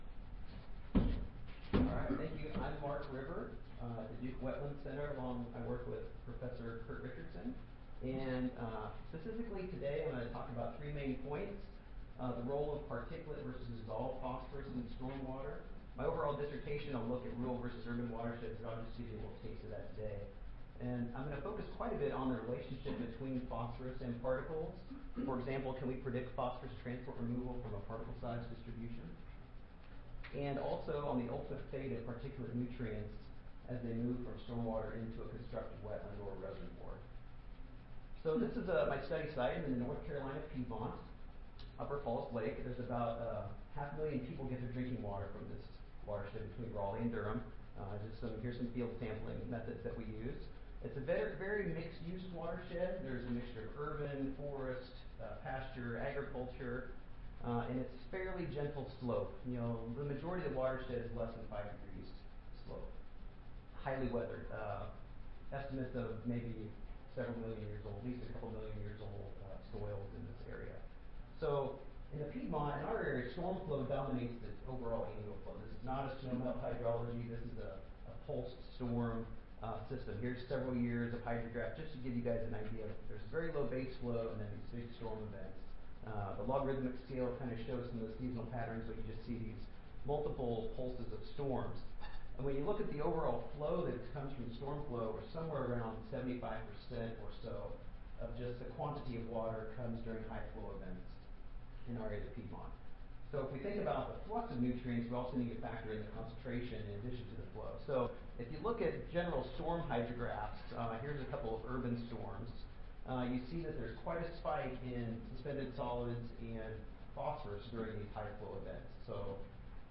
Duke University Audio File Recorded Presentation